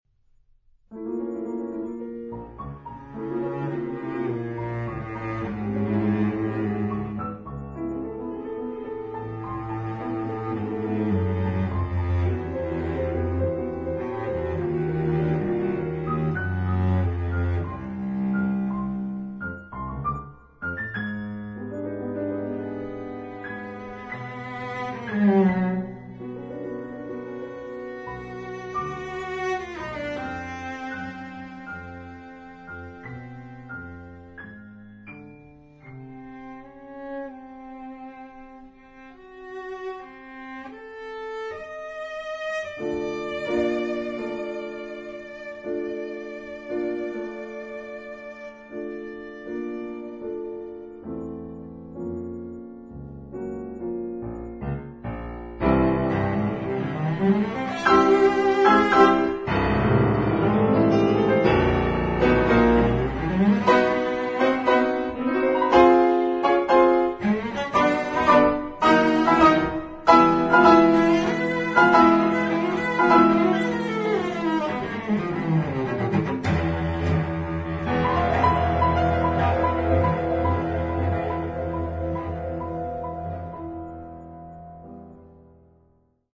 A Sonata for cello and piano